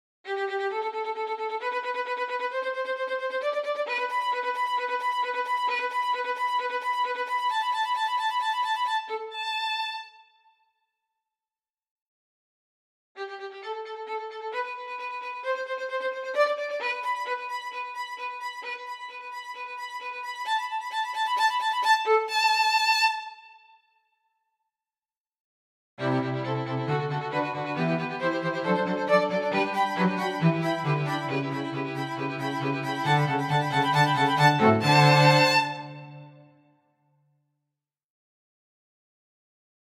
The orchestra plays only fast staccatos.